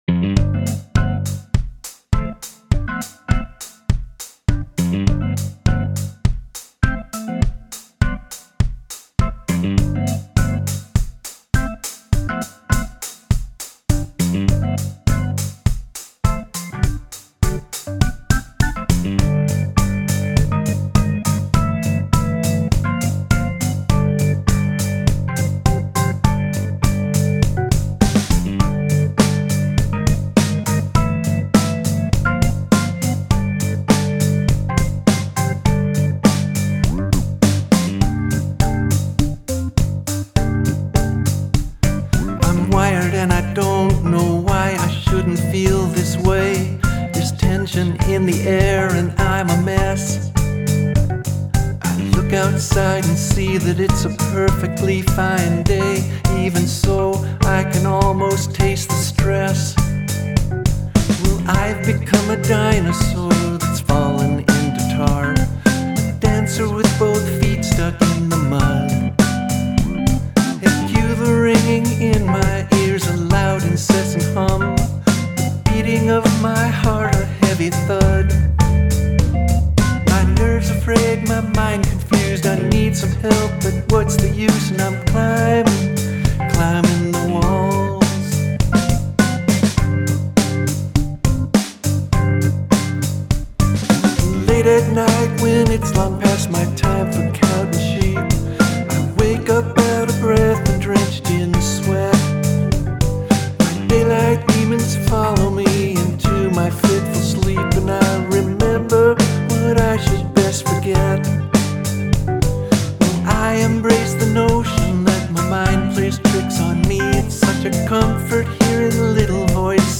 On this one I tried to create a bit of a Steely Dan feel, albeit a very weak imitation.